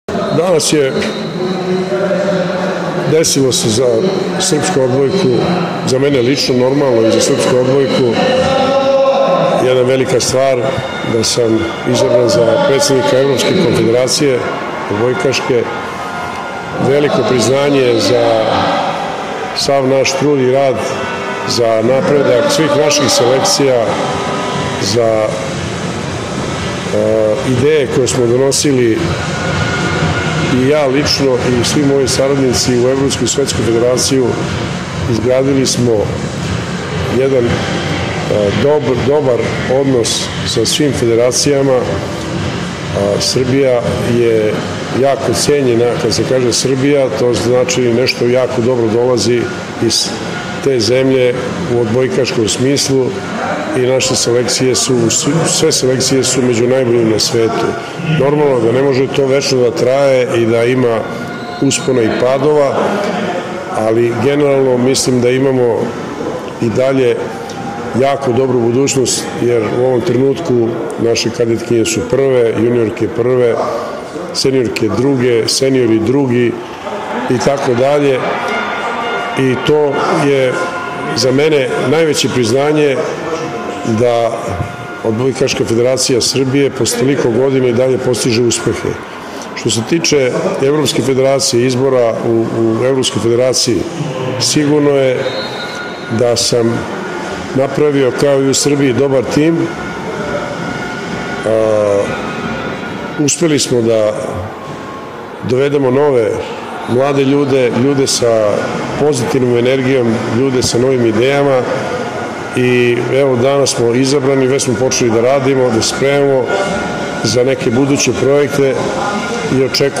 Boričić je u dvorani Armejec dao prvu izjavu za srpske medije posle izbora za predsednika Evropske odbojkaške konfederacije.